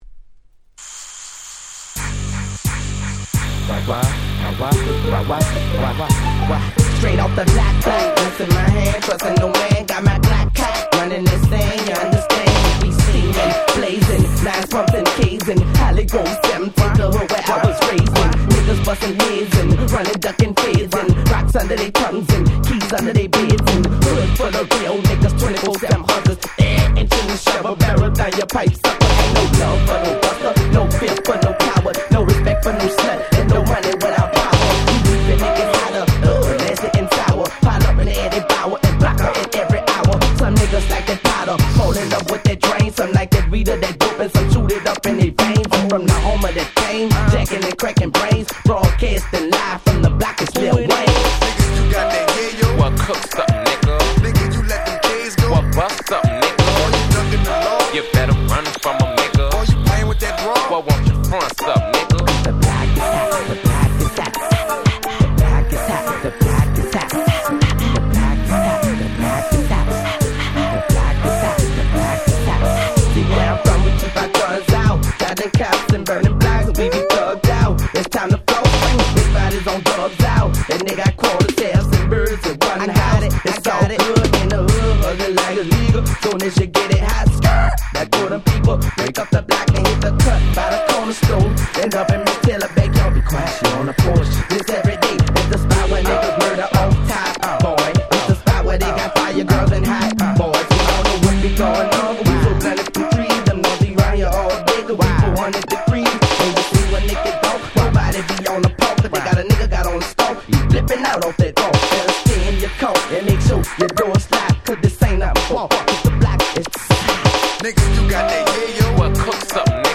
【Media】Vinyl 12'' Single
99' Smash Hit Southern Hip Hop !!